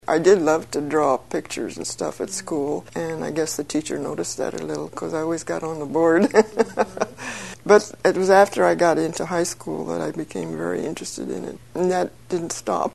The Iowa Women Artists Oral History Project records and preserves the voices of women visual artists in Iowa reflecting on their lives and their artwork.